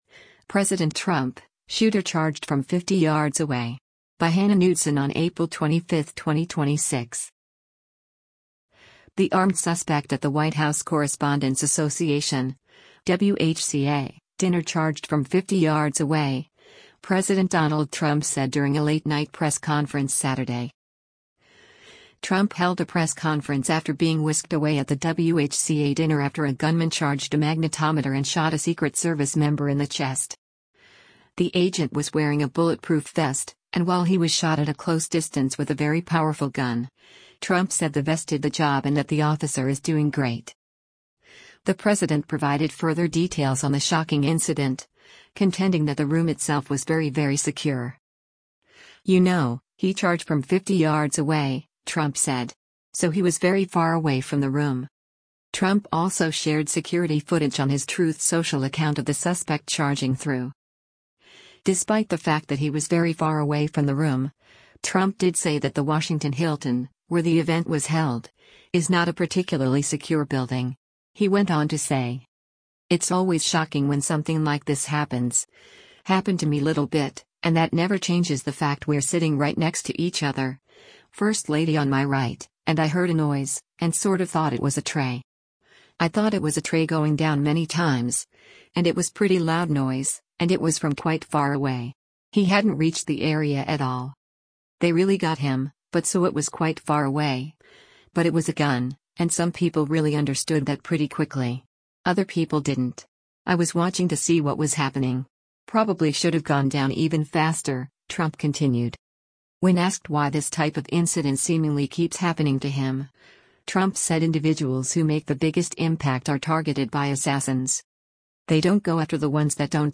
The armed suspect at the White House Correspondents’ Association (WHCA) Dinner “charged from 50 yards away,” President Donald Trump said during a late night press conference Saturday.